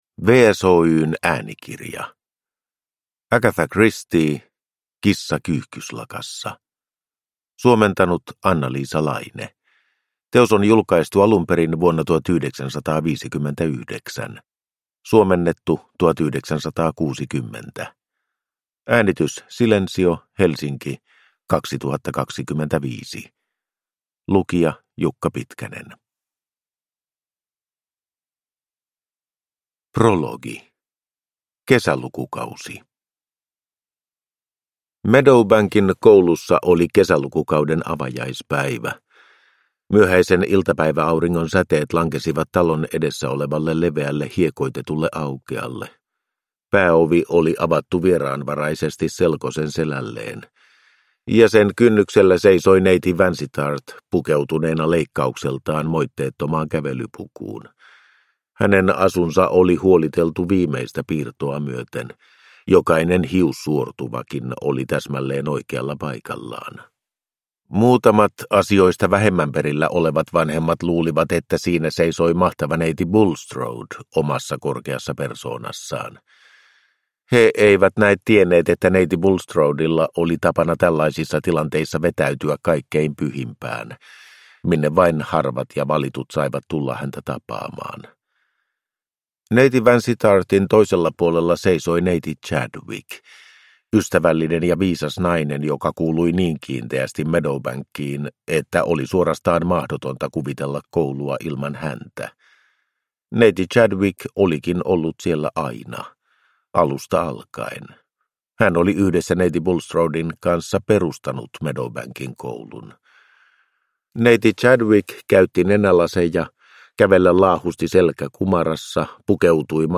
Kissa kyyhkyslakassa – Ljudbok